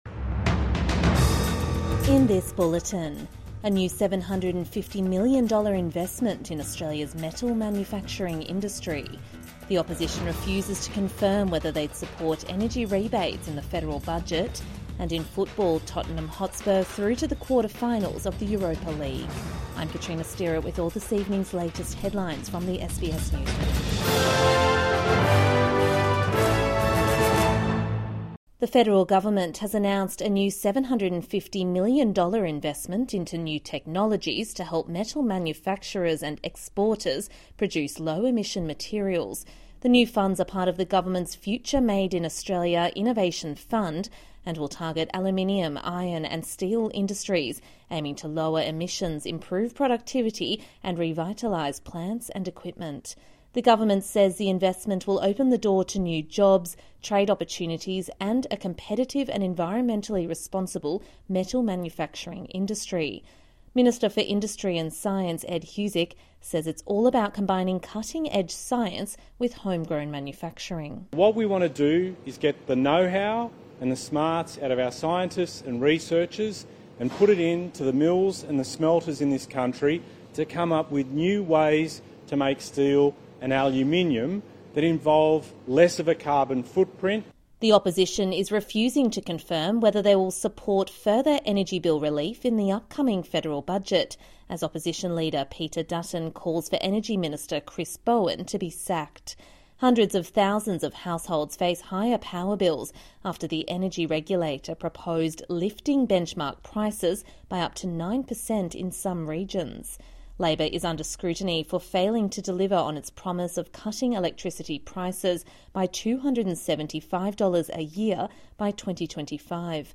Evening News Bulletin 14 March 2025